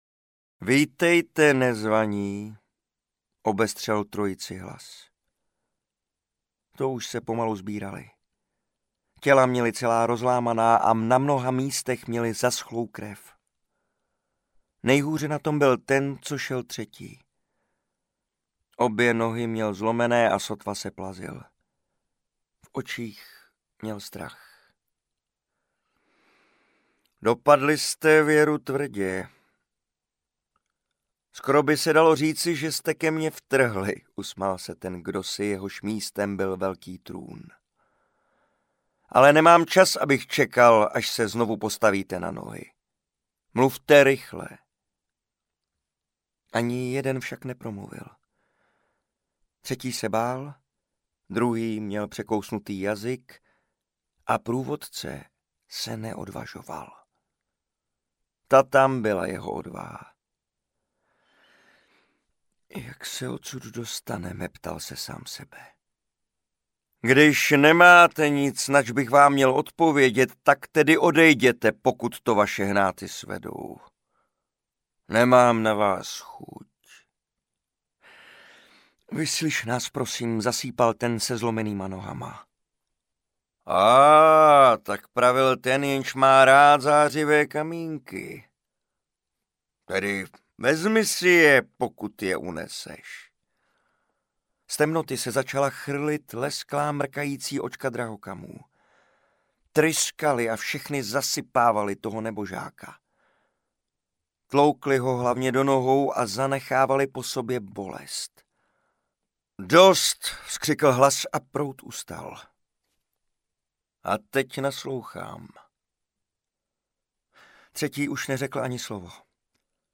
Legenda o velké zimě audiokniha
Ukázka z knihy